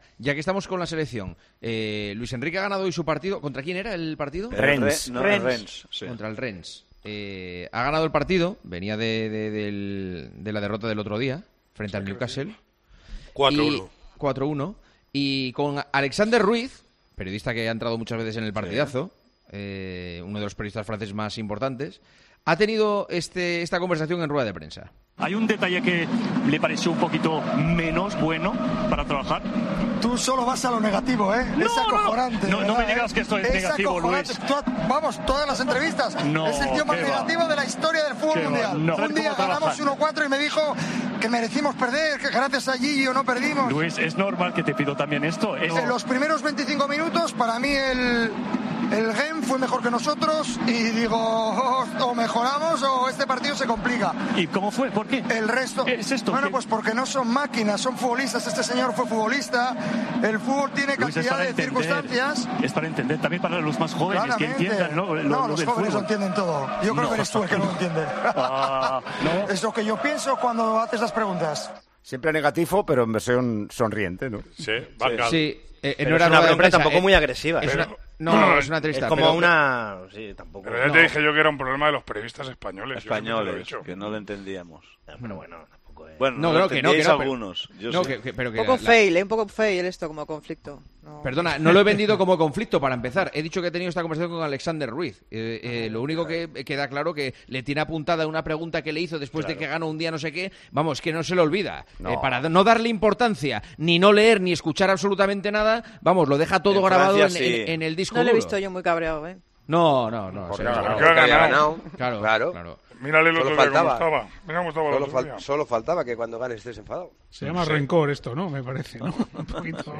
Este momento de la entrevista fue tratado anoche en El Tertulión de los domingos con Juanma Castaño y este fue análisis que se hizo de lo sucedido entre Luis Enrique y el periodista.